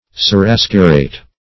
Seraskierate \Se*ras"kier*ate\, n. The office or authority of a seraskier.